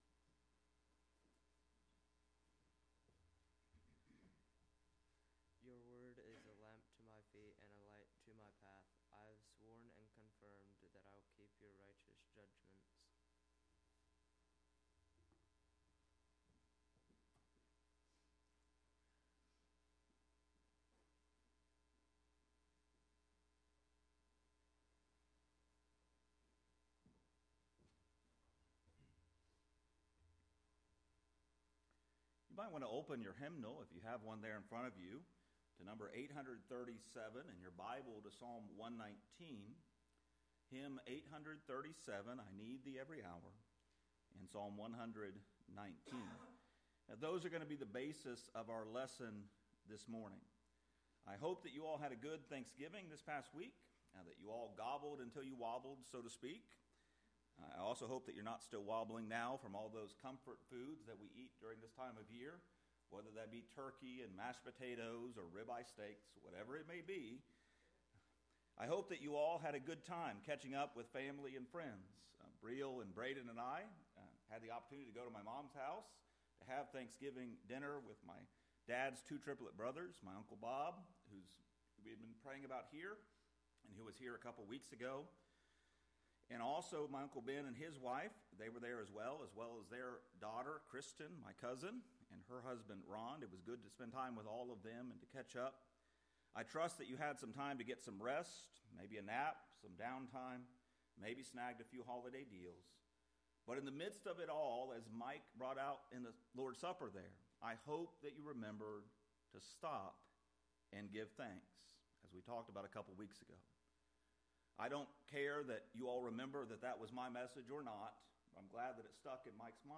The sermon’s overarching message is that Christians need God “every hour,” and that this need is practically expressed through daily devotion to God and His word.